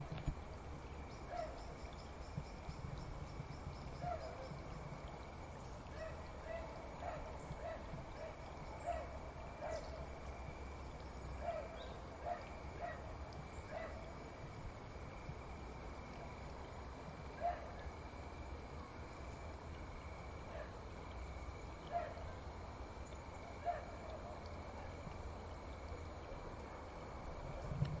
描述：蝉和其他声音在晚上。
Tag: 鸟类 蟋蟀 领域 氛围 性质 现场录音 晚上